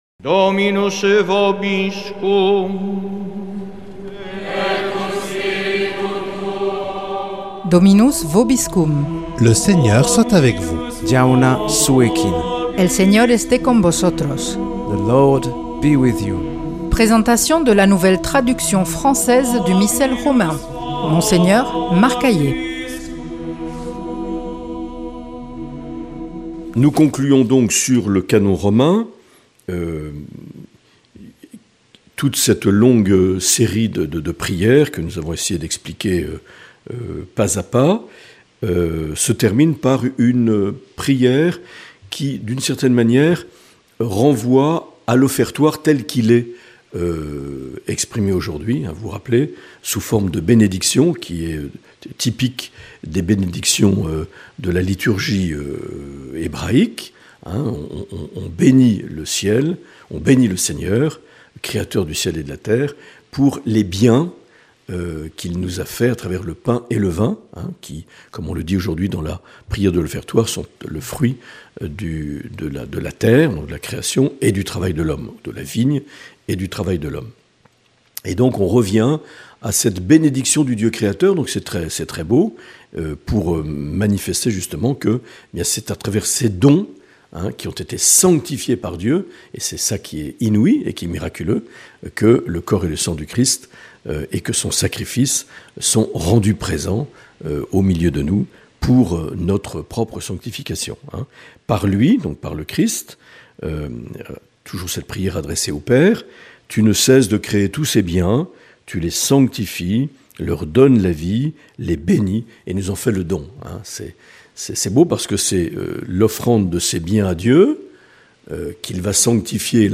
Une émission présentée par Monseigneur Marc Aillet